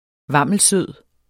Udtale [ ˈvɑmˀəl- ]